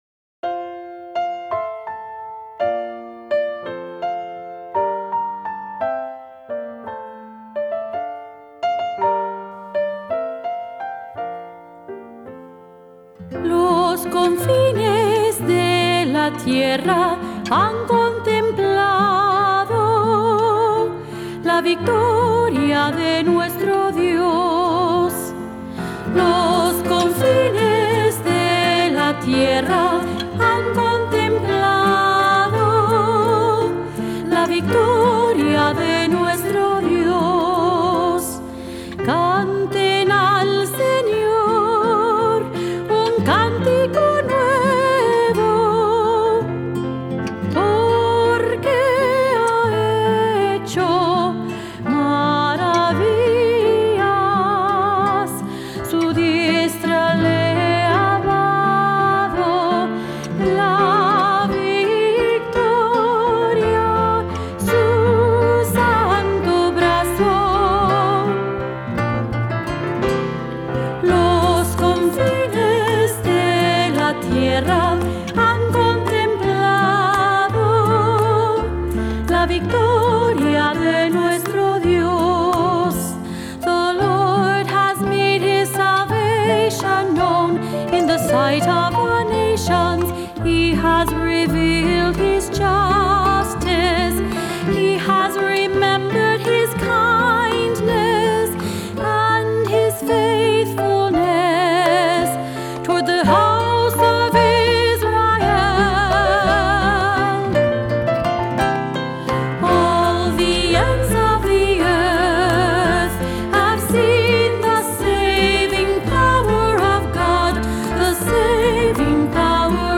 Voicing: Cantor,SATB, assembly